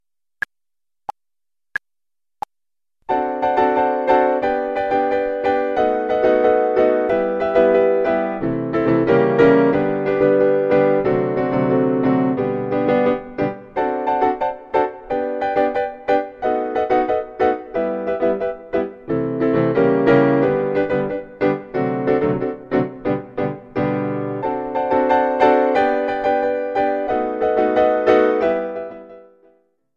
Version only with the piano   US$ 2.50